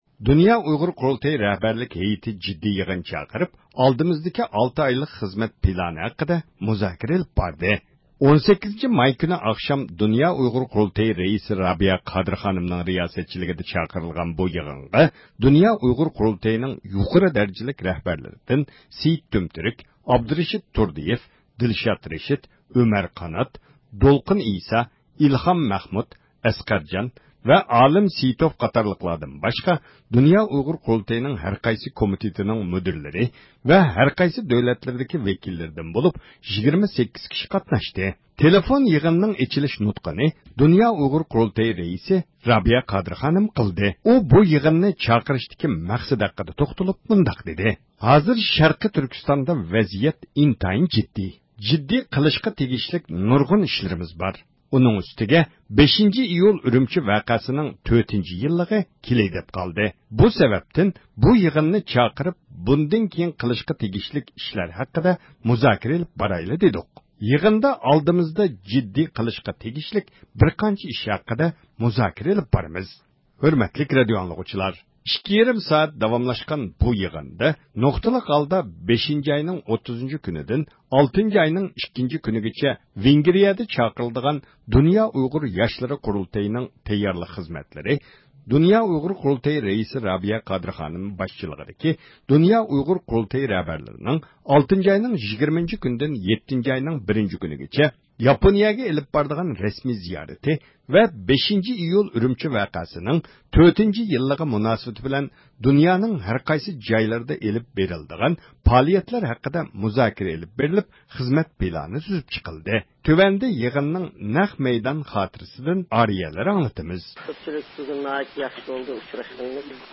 تېلېفون يىغىنىنىڭ ئېچىلىش نۇتقىنى د ئۇ ق رەئىسى رابىيە قادىر خانىم قىلدى.
تۆۋەندە يىغىننىڭ نەق مەيدان خاتىرىسىنى ئاڭلىتىمىز..